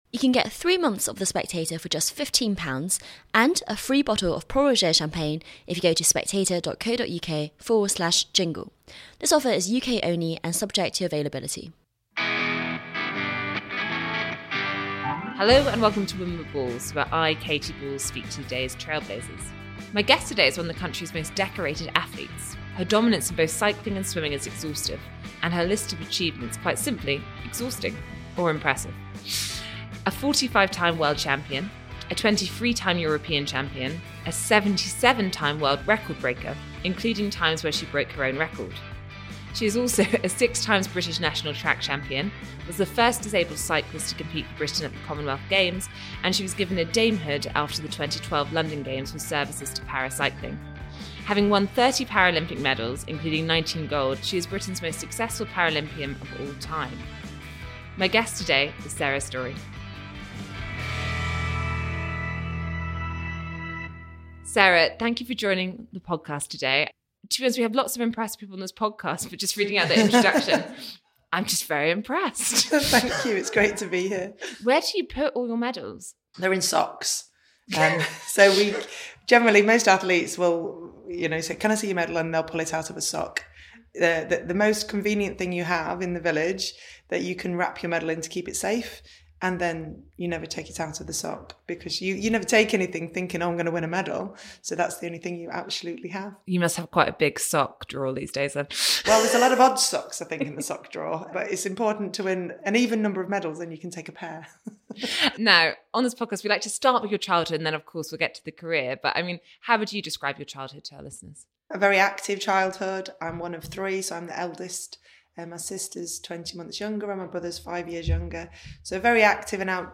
On the podcast, Sarah talks to Katy Balls about switching from swimming to cycling, the influence of bullying at school and the funding disparity that Paralympians face. She also talks about working with Dan Jarvis and Andy Burnham on improving cycling infrastructure, as well as her preparations for the next Olympics – Los Angeles 2028.